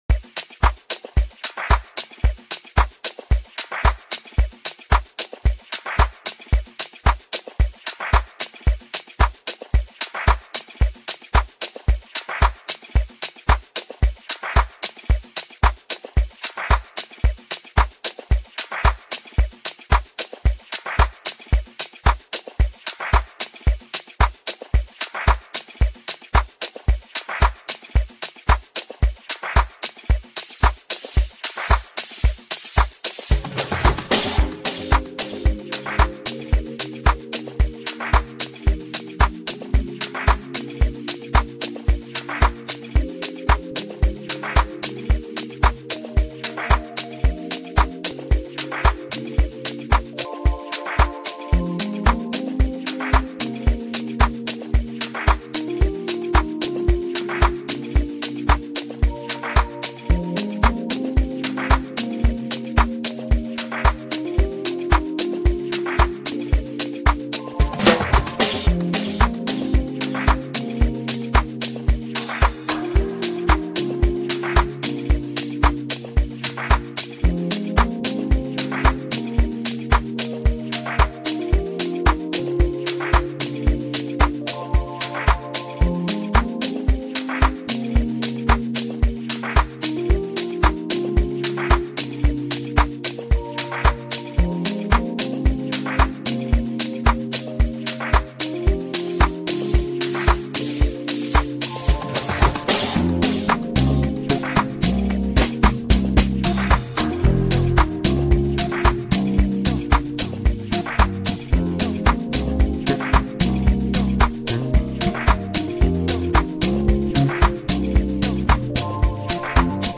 ” offering smooth vocals